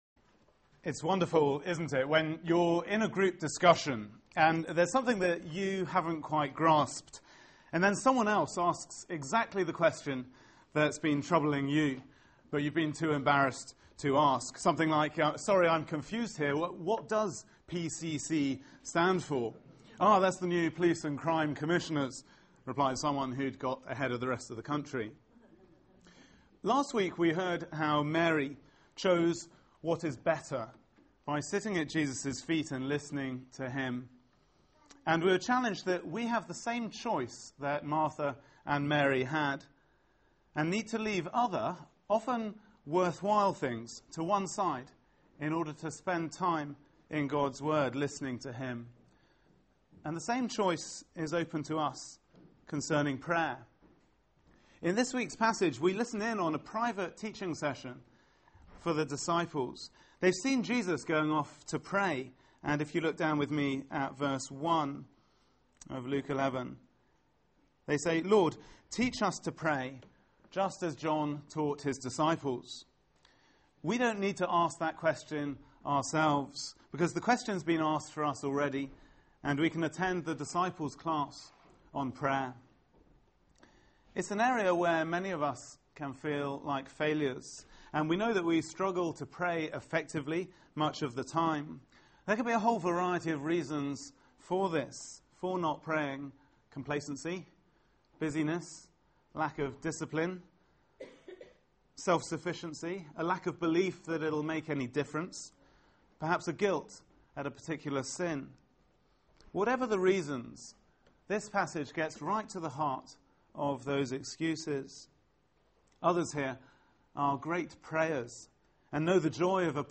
Media for 4pm Service on Sun 18th Nov 2012 16:00 Speaker
Sermon